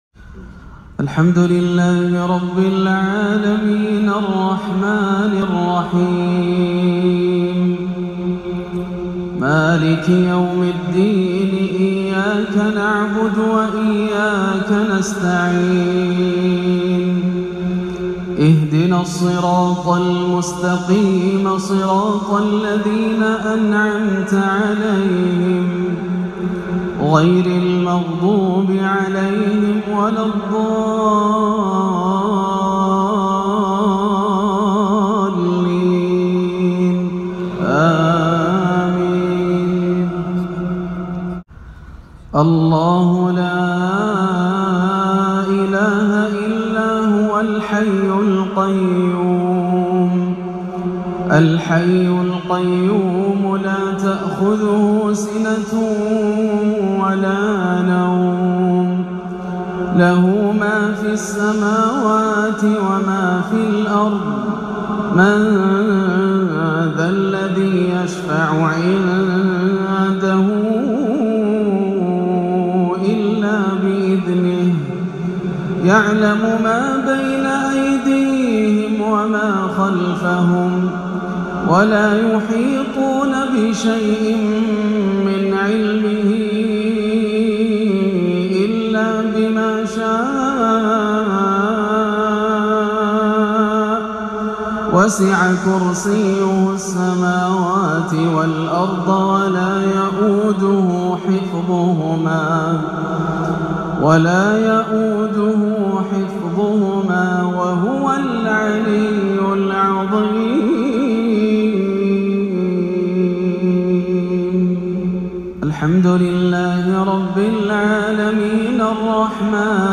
مغرب الإربعاء 16 صفر 1438هـ من جامع الدخيل بحي الشهداء بمدينة الرياض تلاوة بديعة من سورتي البقرة والنور > عام 1438 > الفروض - تلاوات ياسر الدوسري